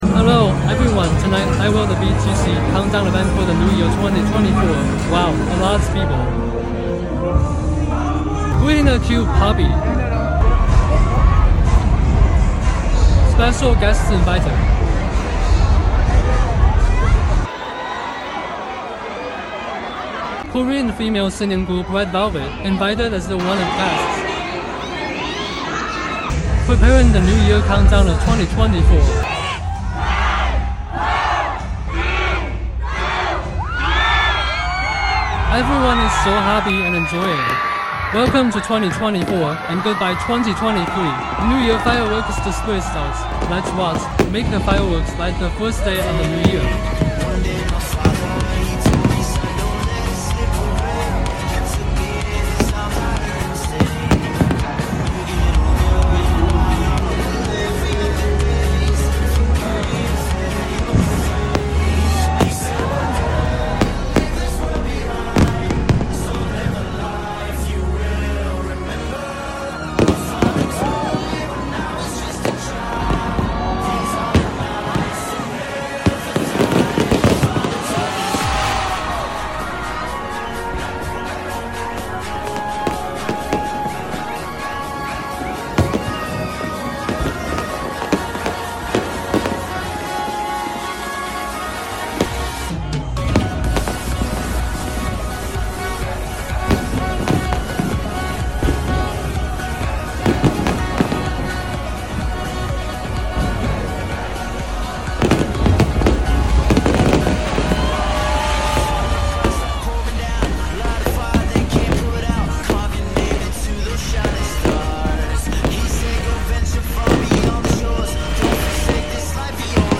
2024 new year countdown in sound effects free download
2024 new year countdown in BGC